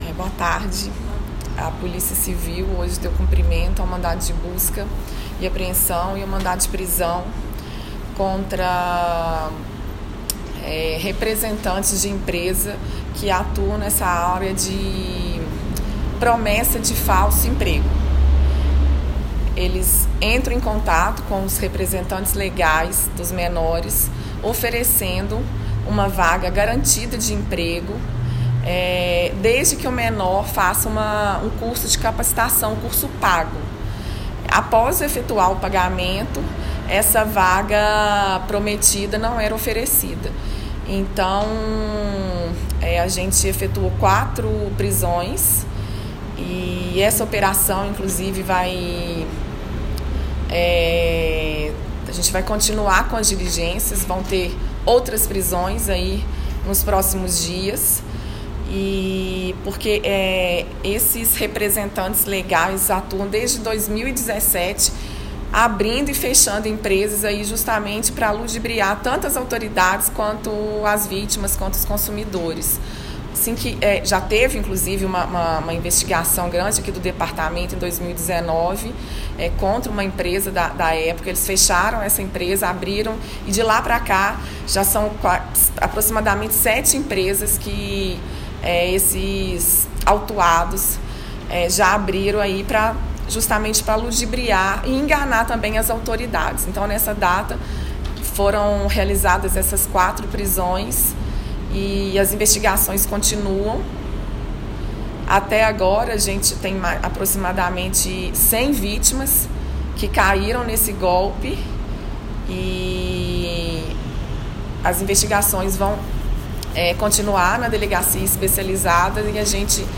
Coletiva.mp3